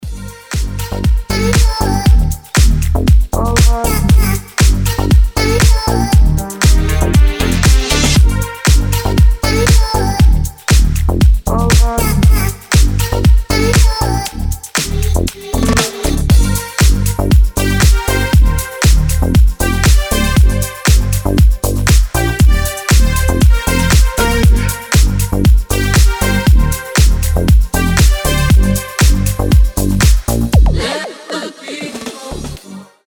• Качество: 320, Stereo
deep house
заводные
nu disco
детский голос
Indie Dance
Заводная танцевальная музыка